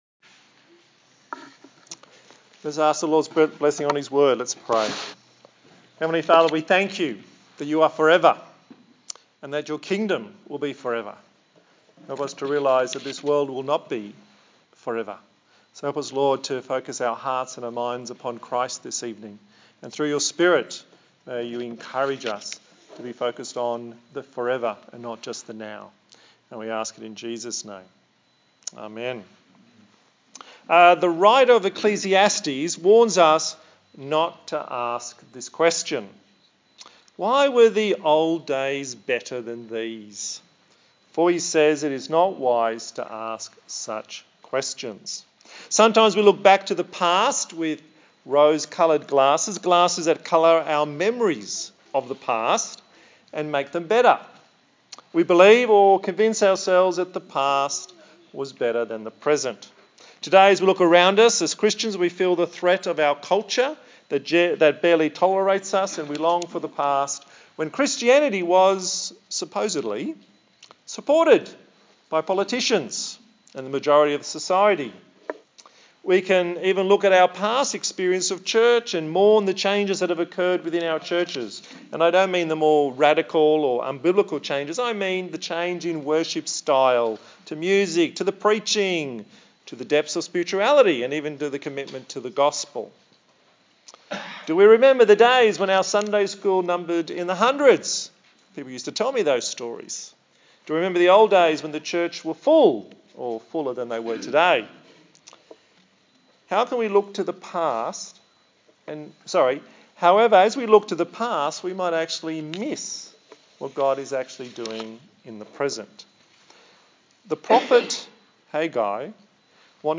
A sermon in the series on the book of Haggai. God will glorify himself and we will be blessed. Headings: v1-3 Disappointment with the new temple; vv4-5 Don't be afraid; vv6-9 Greater Glory.